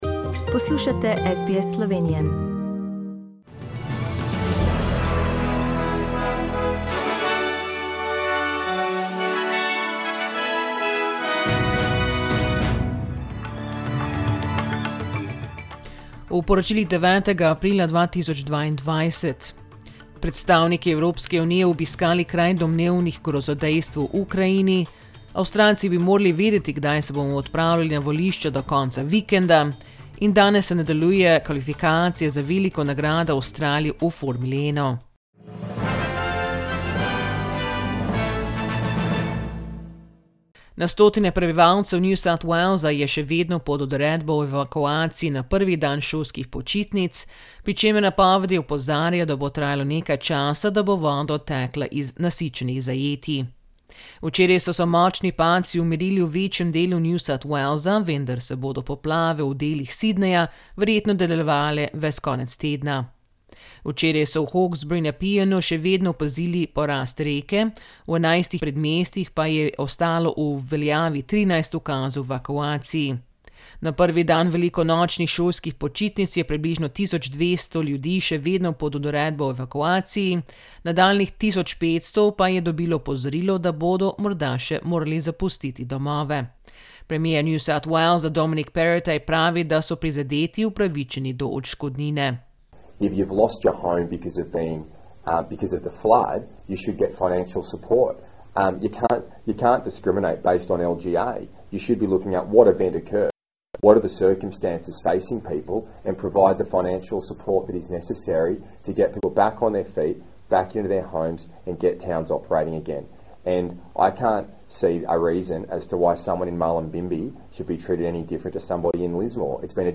Poročila Radia SBS v slovenščini 9.aprila